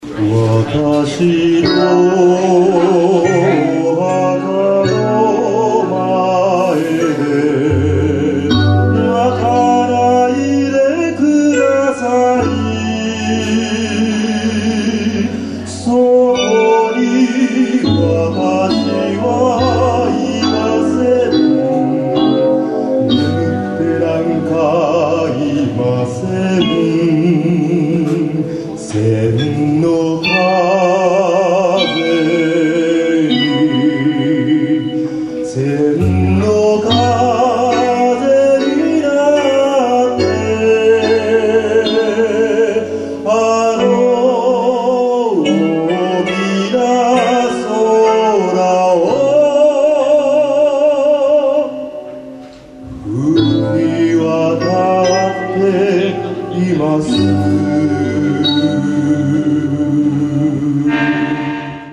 キーボードの弾き語りで行いました。